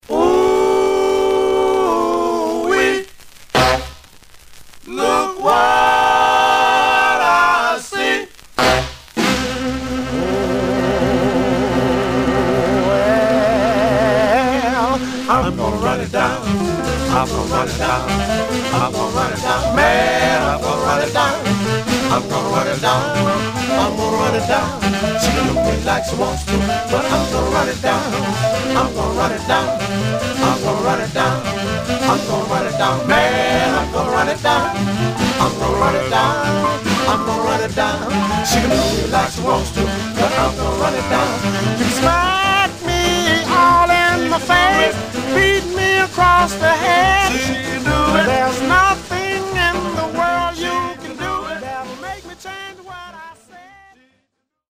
Some surface noise/wear
Mono
Male Black Group